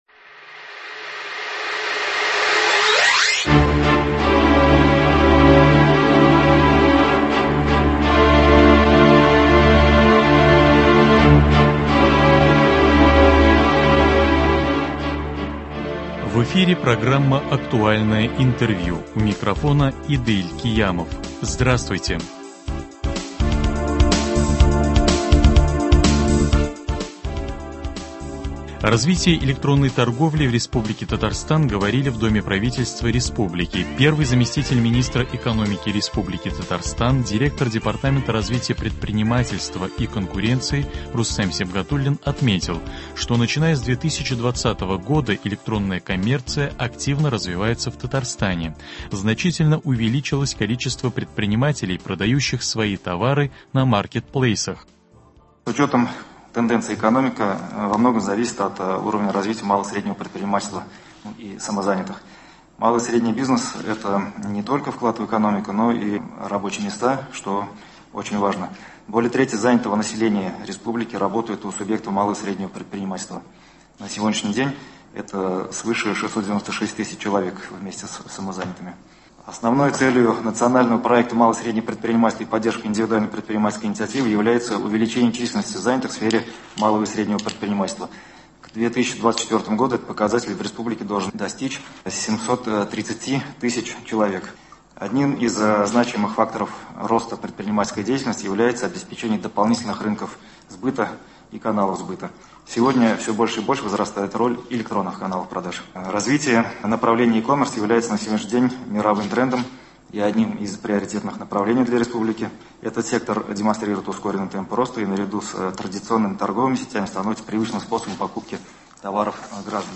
Актуальное интервью (08.09.2021)